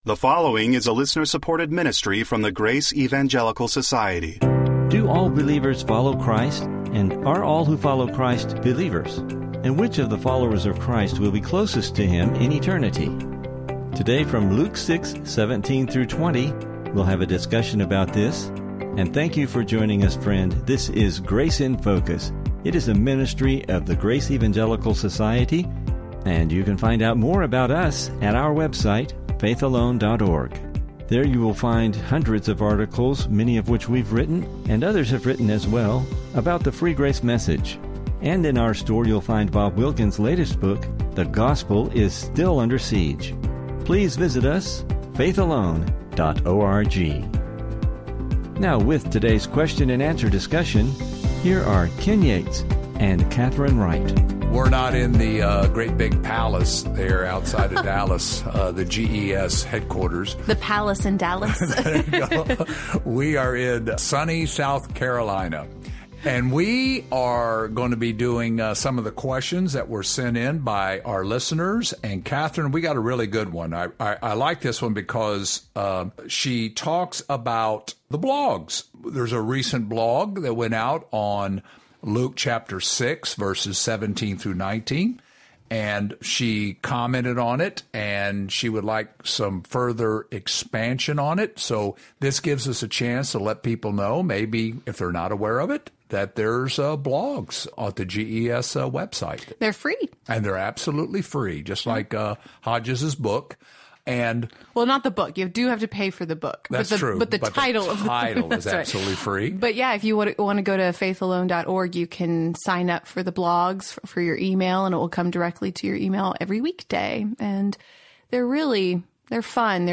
Welcome to Grace in Focus radio.
Please listen for an interesting discussion and lessons related to this passage.